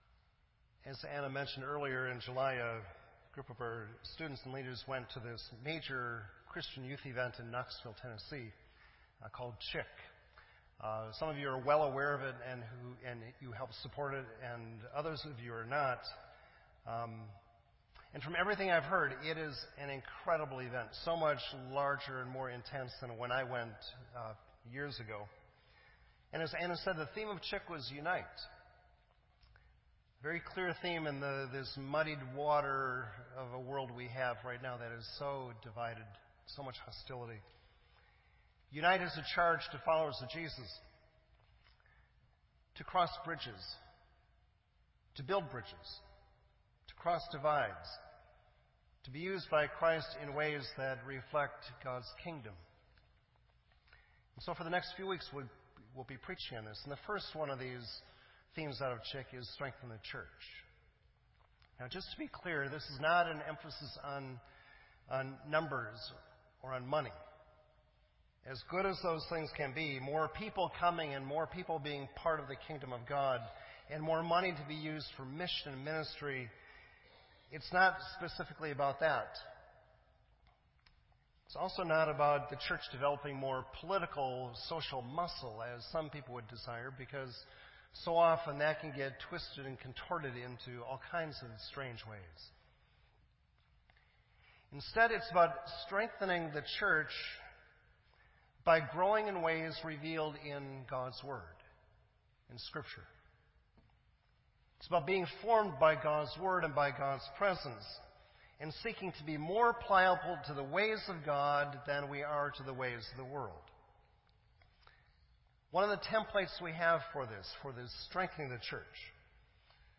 This entry was posted in Sermon Audio on September 24